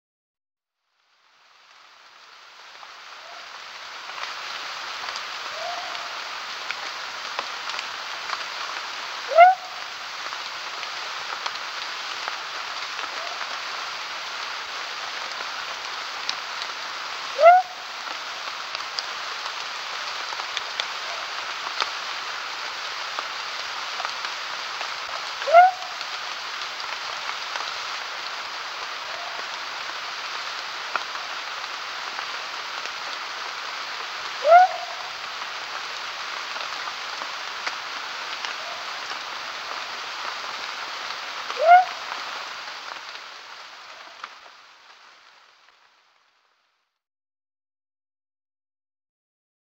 Sonothèque des amphibiens de Guyane - Cerato
Leptodactylus-pentadactylus-CM-PG.mp3